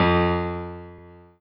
piano-ff-21.wav